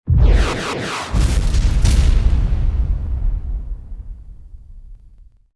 missile2.wav